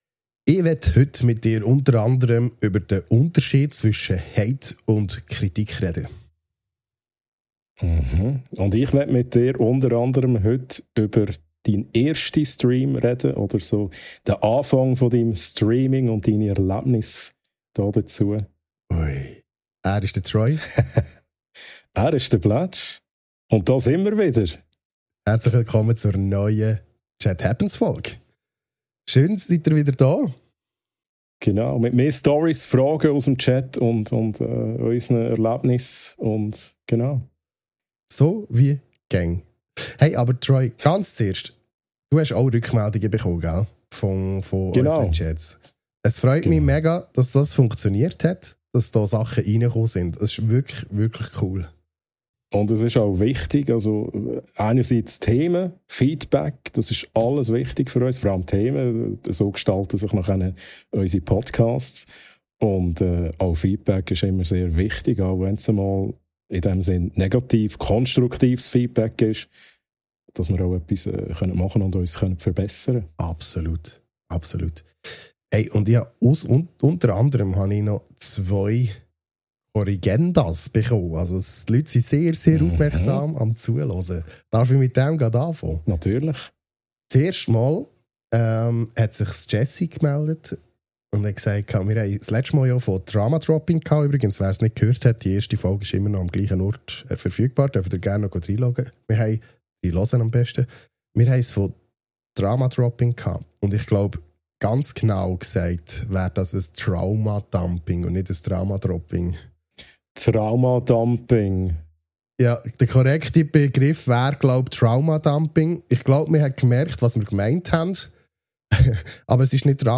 Schweizerdeutsch, reflektiert und direkt – viel Spass mit Episode 2 von «Chat Happens»!